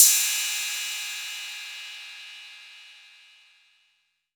TR 808 Crash Cymbal Free sound effects and audio clips
• Crash D# Key 16.wav
Royality free crash cymbal sound clip tuned to the D# note. Loudest frequency: 7584Hz
crash-d-sharp-key-16-gsx.wav